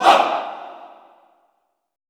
MALE OOAH -R.wav